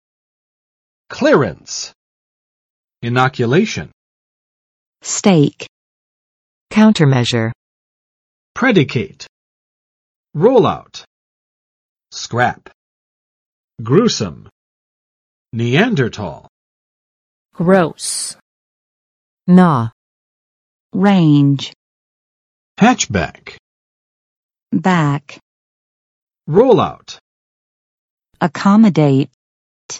[ˋklɪrəns] n.（船只）结关；出入港许可证